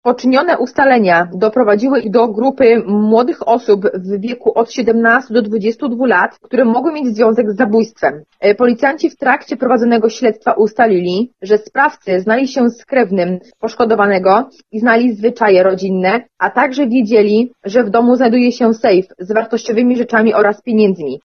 powiedziała w rozmowie z Radiem Kolor – sierżant sztabowa